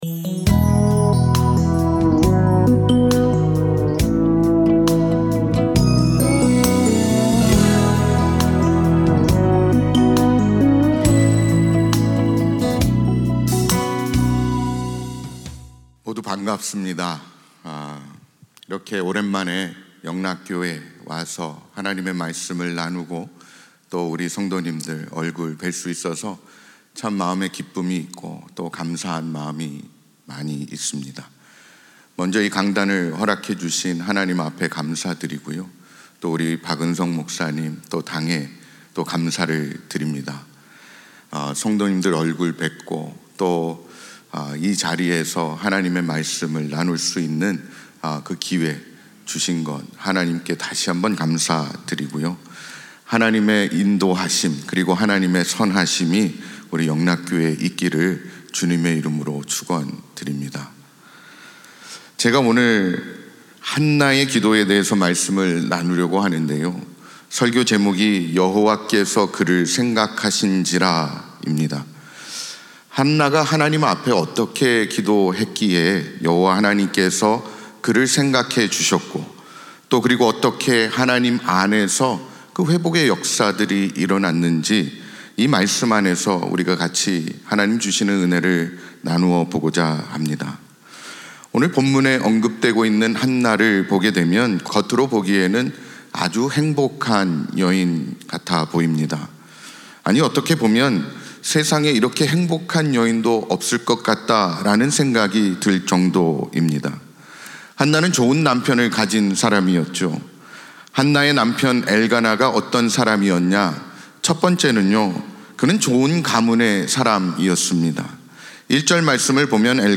특별 새벽 집회